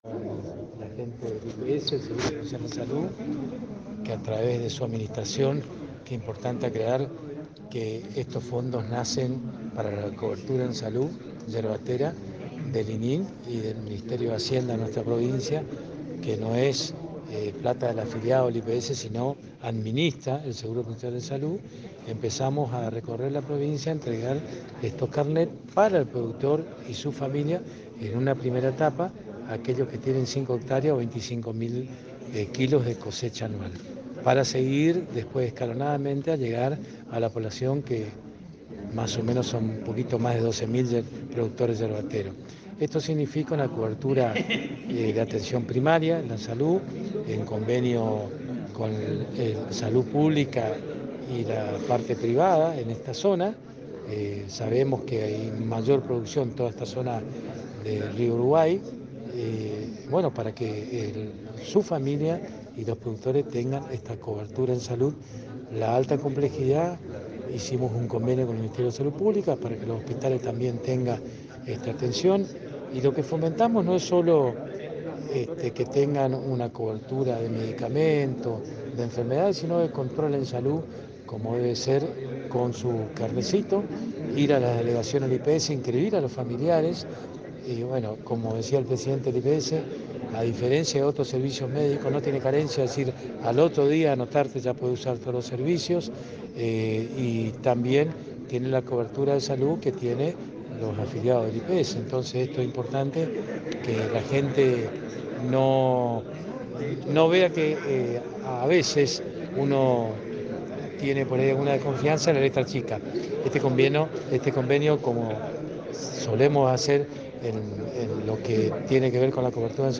Hoy por la mañana se realizó entrega de Carnet de Instituto de Previsión Social (IPS) a más de 100 productores yerbateros. La misma, se llevó a cabo en el Cine teatro de Oberá ubicado en la calle San Martín 1049.
Audio: Vicegobernador, Dr. Carlos Arce